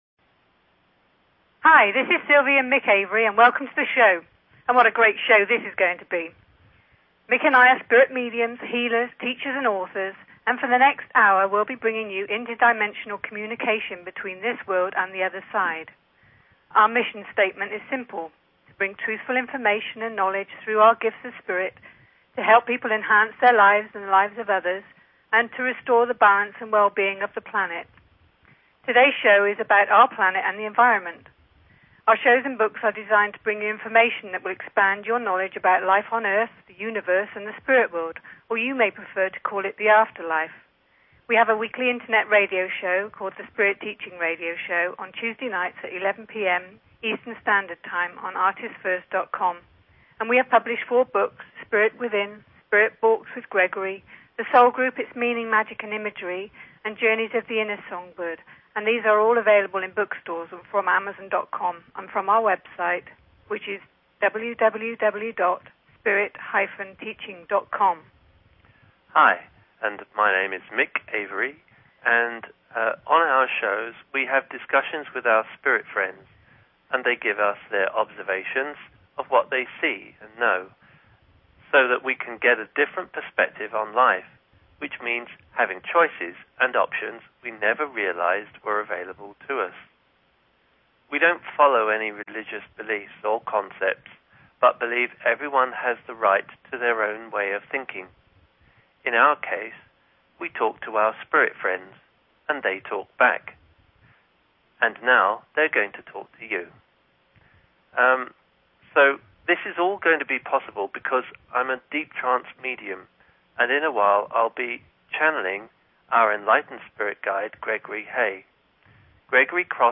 Wildcard Fridays with various Hosts on BBS Radio!